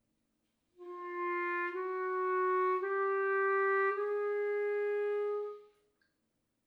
on modern clarinet
example of throat register